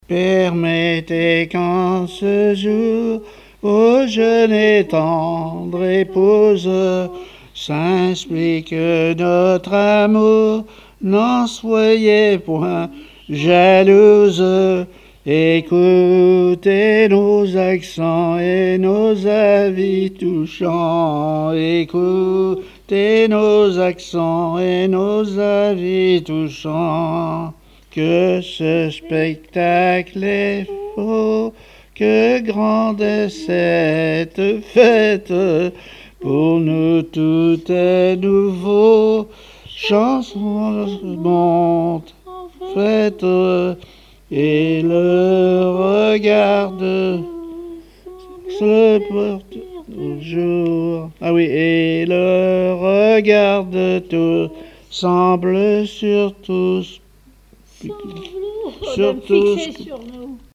circonstance : fiançaille, noce ;
Genre strophique
répertoire musical au violon
Pièce musicale inédite